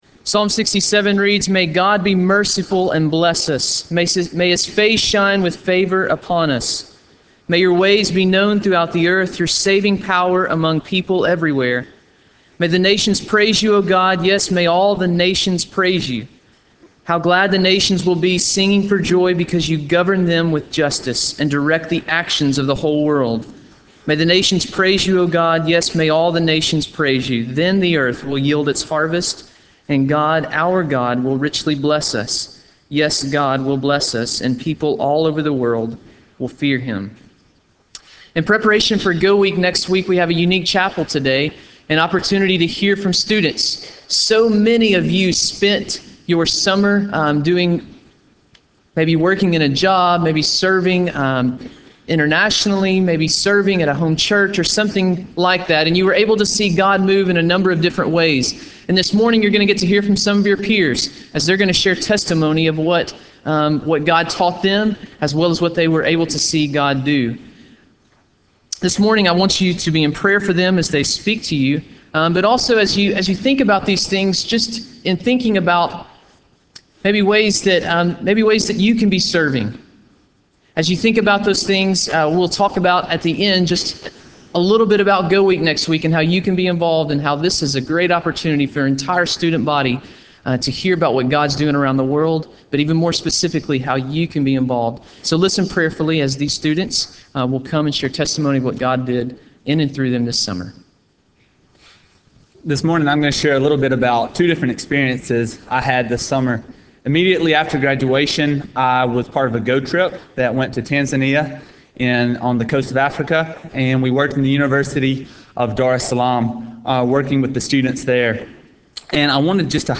Chapel: Student Summer Ministry Experiences